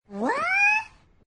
pig5b.mp3